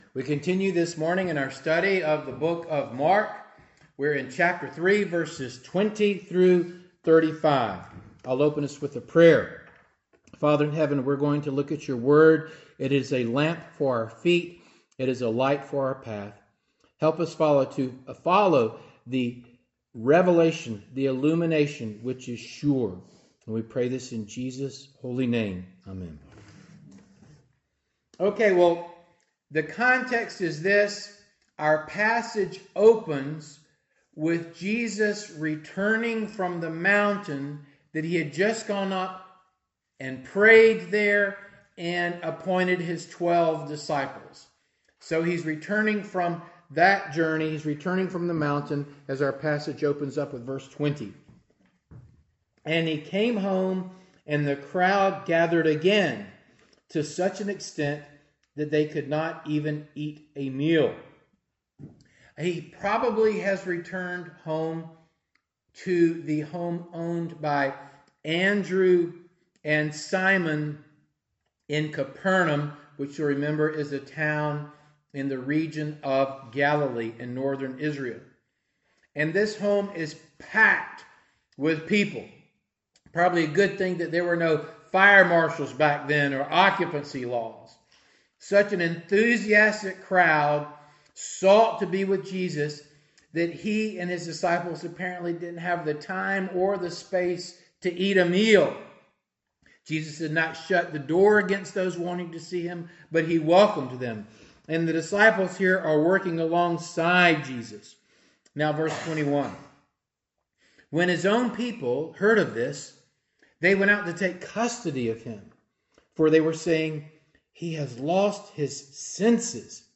Passage: Mark 3:20-35 Service Type: Morning Service